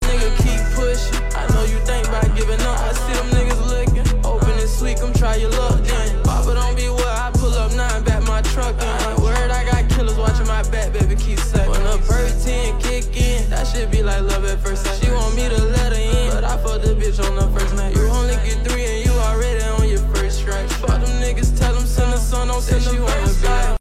With its captivating vocals and infectious rhythm